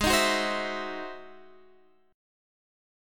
Ab7b13 Chord